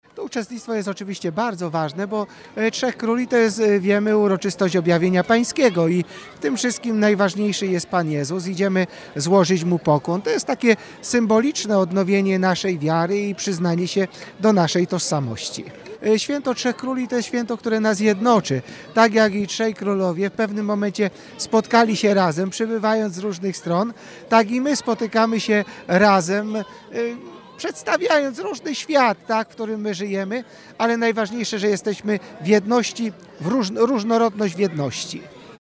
Po raz 13. przez Wrocław przeszedł Orszak Trzech Króli.